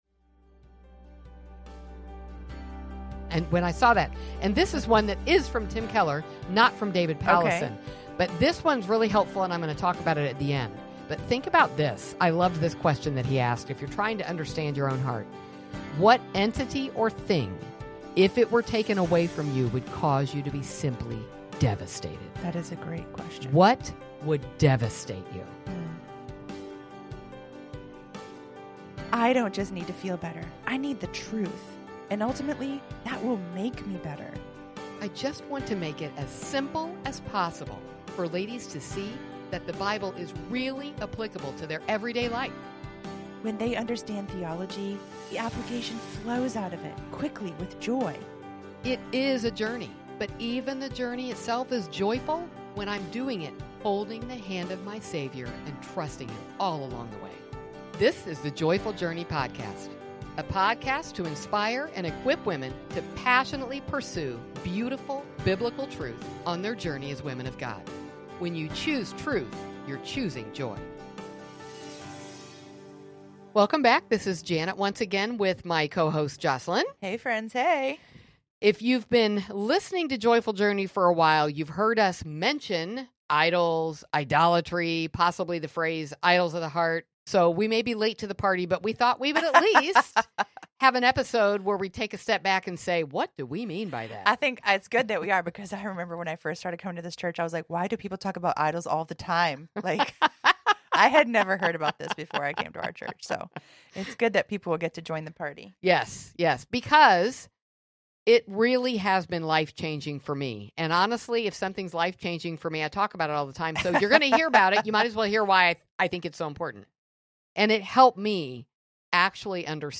Heart-idolatry can stem from virtually any desire—even those that appear "good." The ladies explore how idols subtly enter our lives and offer guidance on identifying which ones may have taken root in your heart.